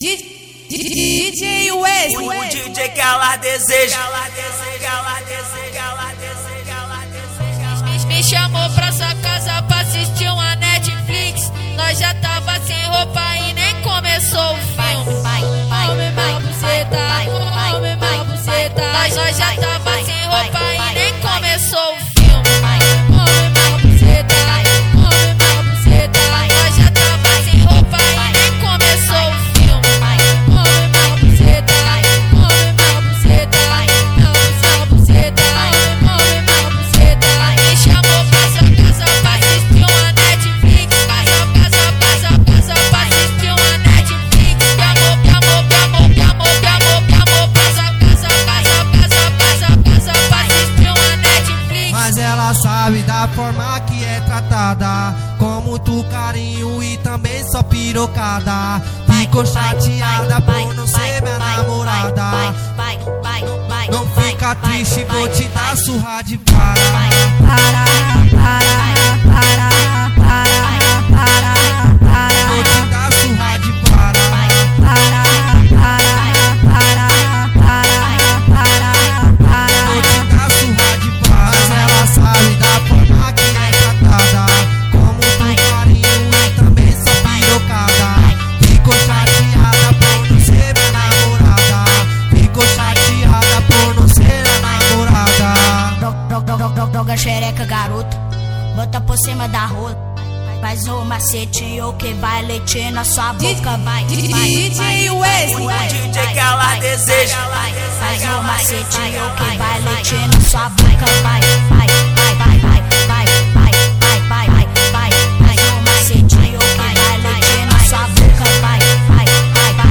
2025-03-27 11:04:42 Gênero: Funk Views